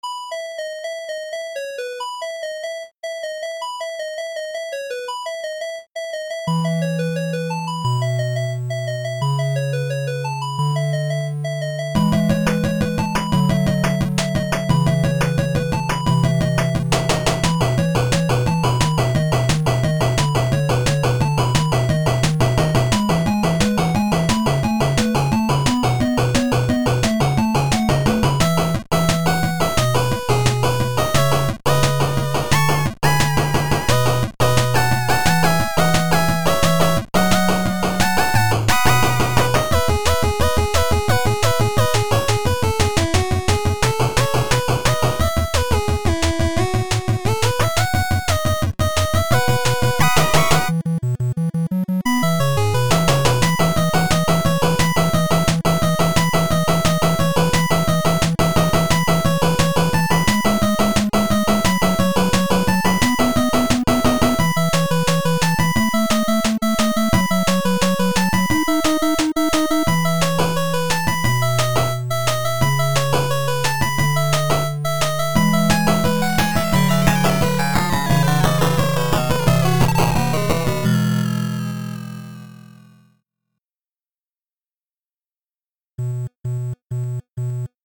some chiptunes